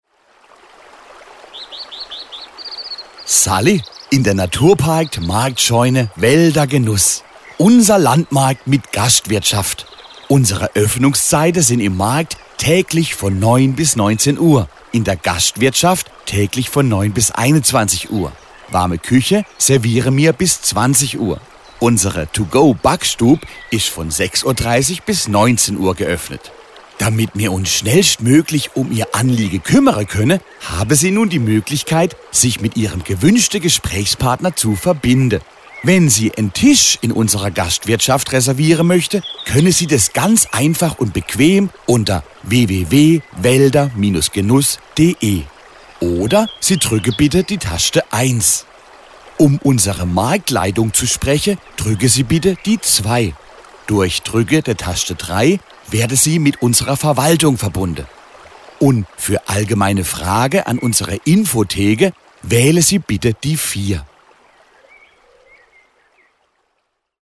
Badische Telefonansage – Telefonansage badisch
Begrüßungsansage